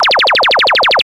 Generic Lasers
Laser Repeated Blasts